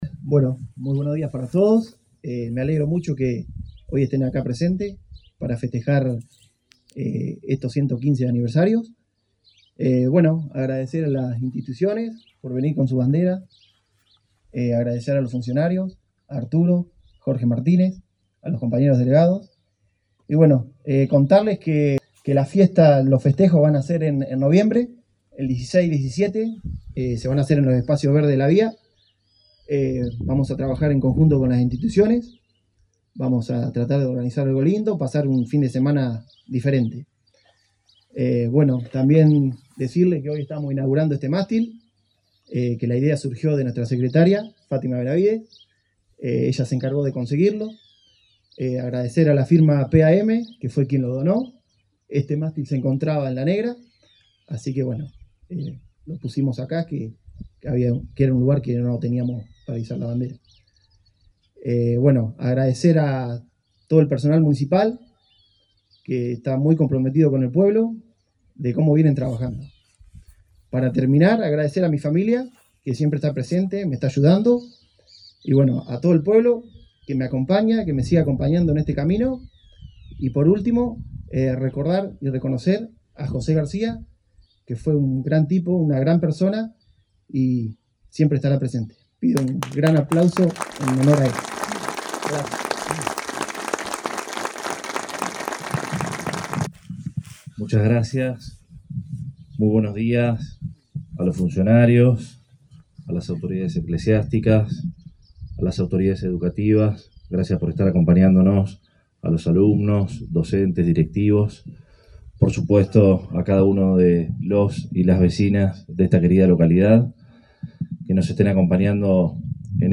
El Intendente Arturo Rojas encabezó el acto protocolar por los 115 años desde de fundación de la localidad, que tendrá sus festejos en noviembre.
El delegado Facundo Iglesias, funcionarios, instituciones y vecinos también fueron parte de la ceremonia en el la Plaza Infantil Delia Alvear de Ocampo.